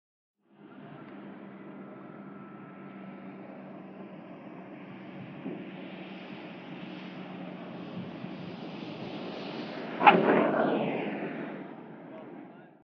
AIRCRAFT PROP GLIDER: EXT: Landing, wheel chirp. Watch voices at end.